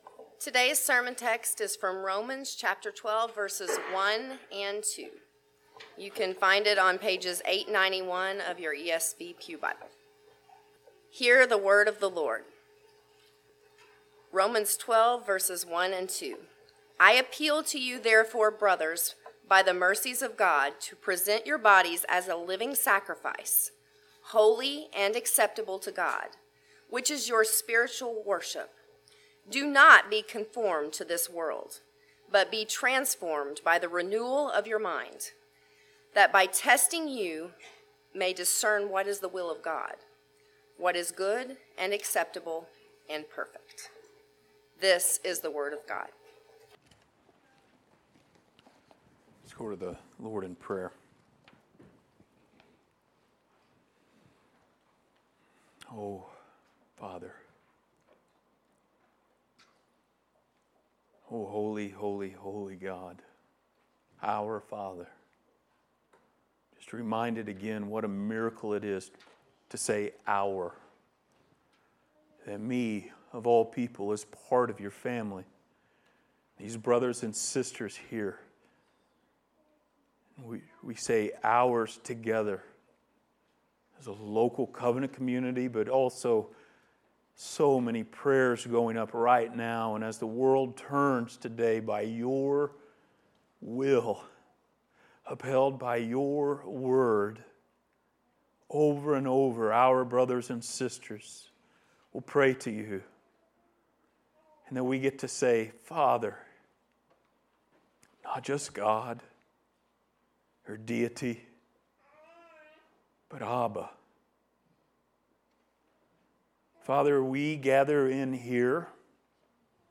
Passage: Romans 12:1-2 Service Type: Sunday Morning